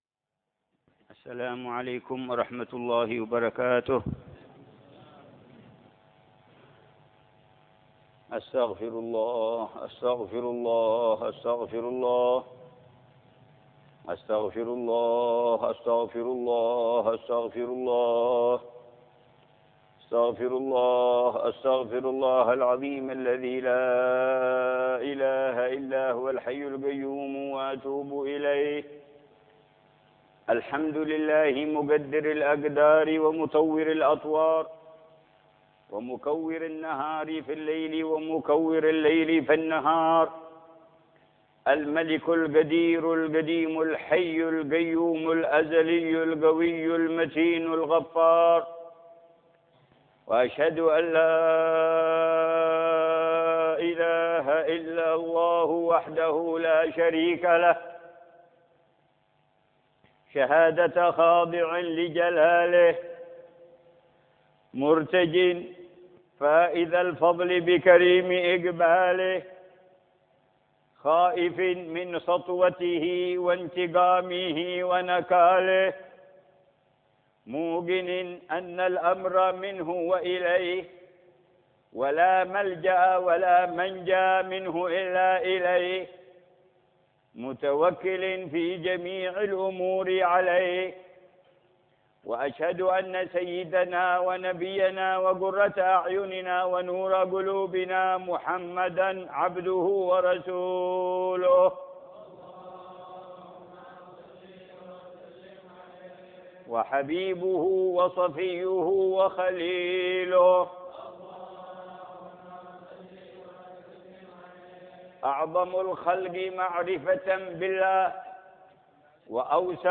خطبة الخسوف 15محرم 1433هـ
خطبة خسوف القمر في مصلى أهل الكساء بدار المصطفى بتريم يوم السبت 15محرم 1433هـ